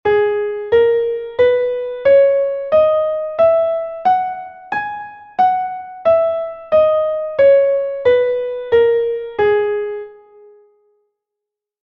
Labm (audio/mpeg)